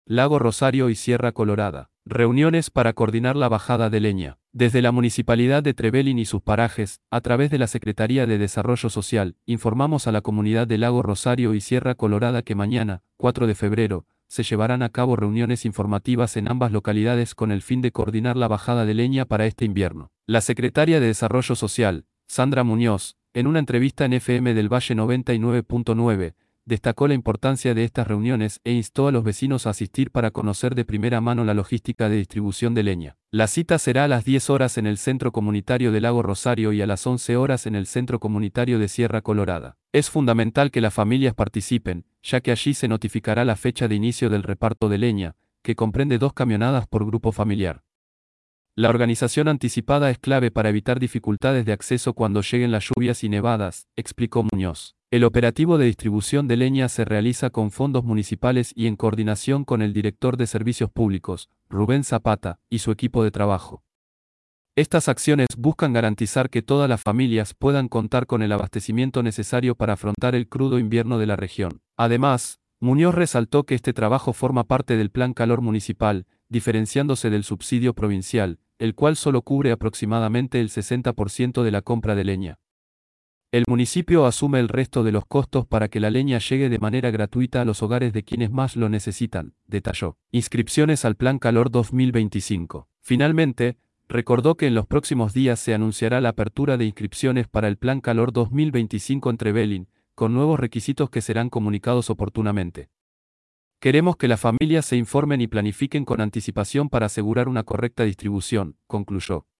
La secretaria de Desarrollo Social, Sandra Muñoz, en una entrevista en FM del Valle 99.9, destacó la importancia de estas reuniones e instó a los vecinos a asistir para conocer de primera mano la logística de distribución de leña.